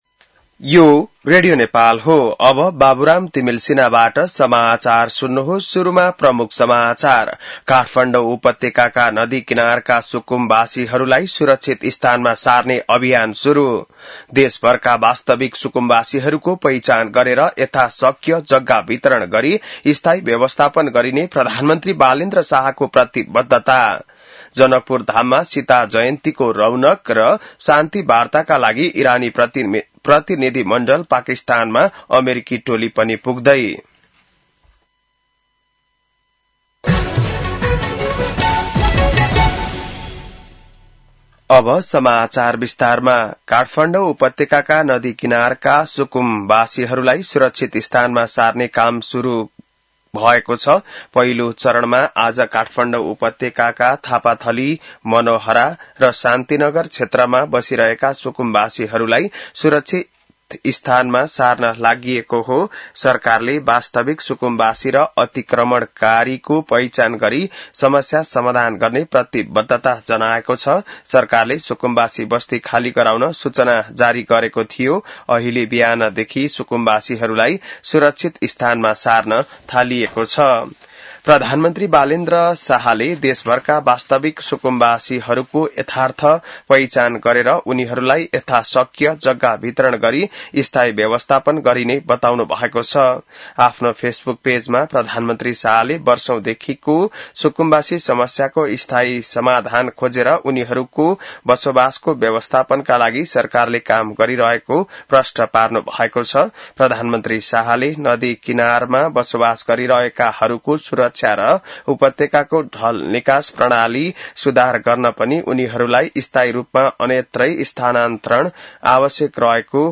बिहान ९ बजेको नेपाली समाचार : १२ वैशाख , २०८३